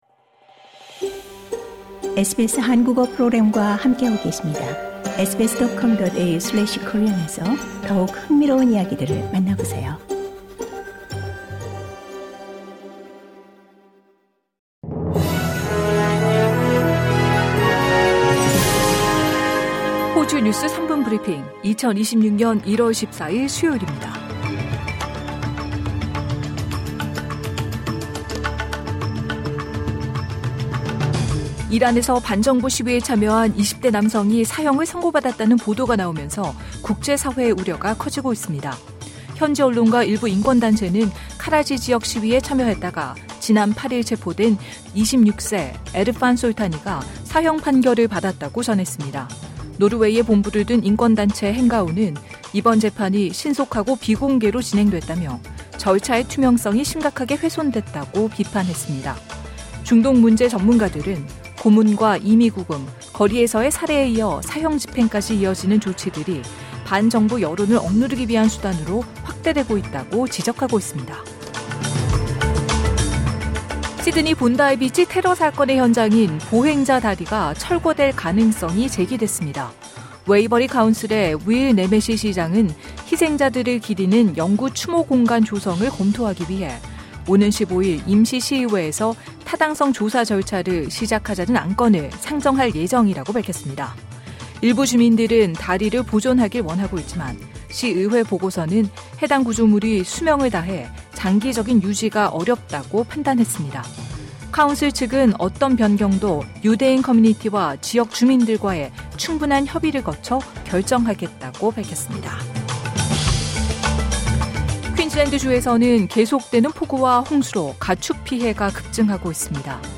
호주 뉴스 3분 브리핑: 2026년 1월 14일 수요일